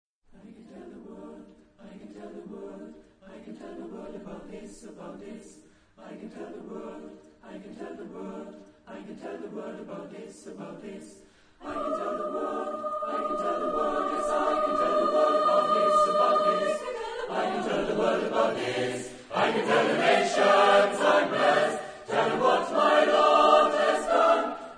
Género/Estilo/Forma: Sagrado ; Espiritual
Tipo de formación coral: SATB  (4 voces Coro mixto )
Tonalidad : mi bemol mayor